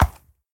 Sound / Minecraft / mob / horse / soft5.ogg